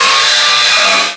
pokeemerald / sound / direct_sound_samples / cries / whirlipede.aif
-Replaced the Gen. 1 to 3 cries with BW2 rips.